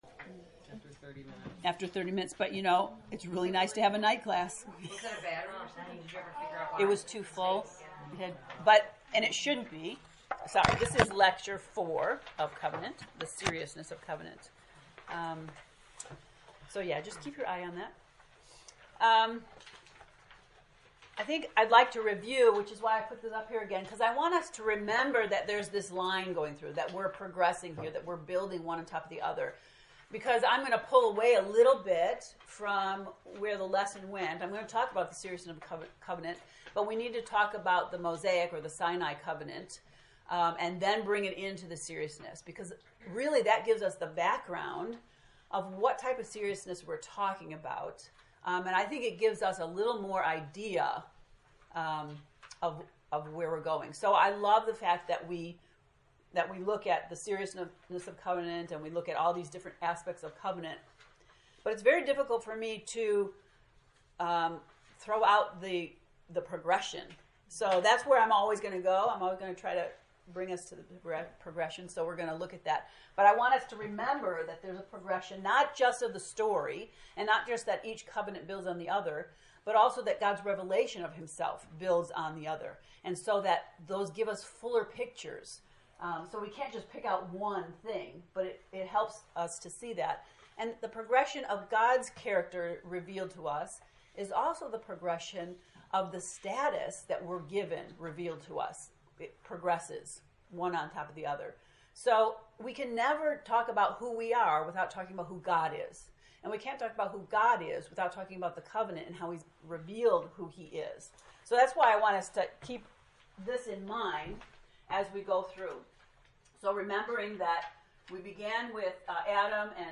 COVENANT lecture 4